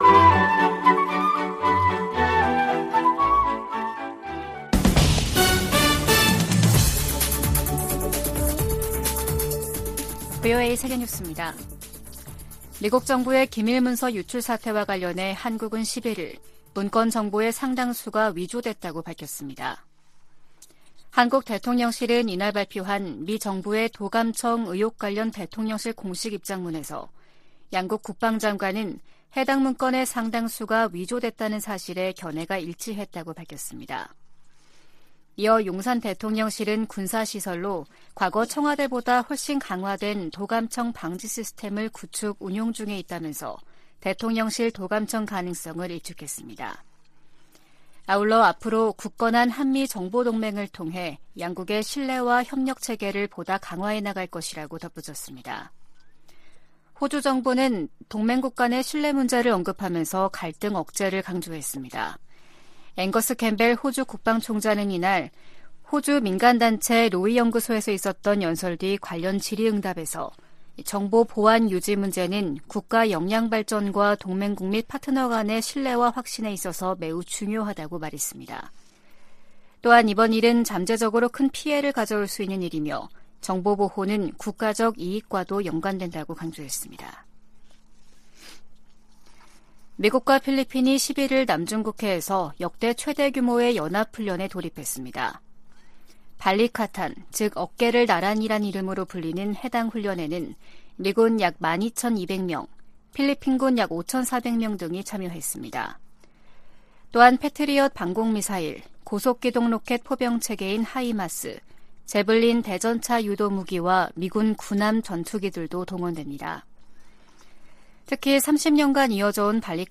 VOA 한국어 아침 뉴스 프로그램 '워싱턴 뉴스 광장' 2023년 4월 12일 방송입니다. 미국 정부는 정보기관의 한국 국가안보실 도·감청 사안을 심각하게 여기며 정부 차원의 조사가 이뤄지고 있다고 밝혔습니다.